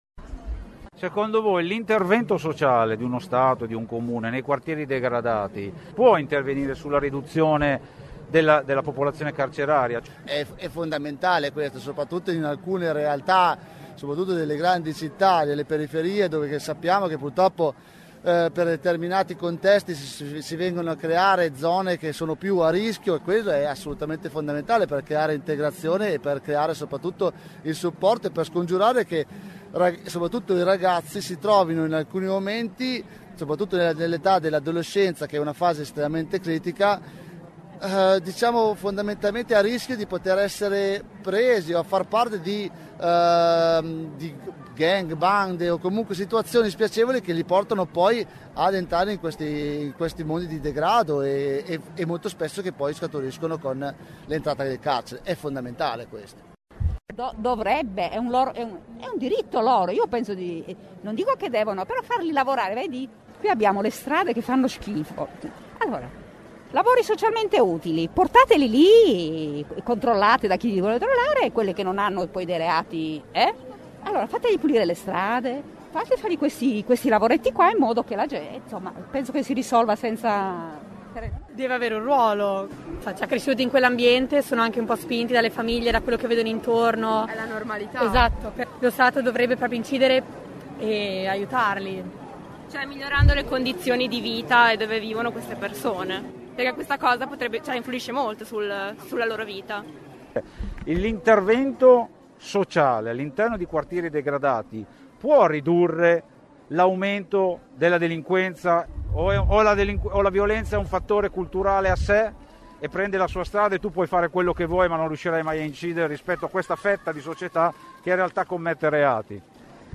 Duranti i numerosi interventi che si sono susseguite con anche collegamenti esterni, (vedi “Senza Quartiere” ), noi abbiamo provato a chiedere ai cittadini presenti, il loro parere rispetto a questo argomento.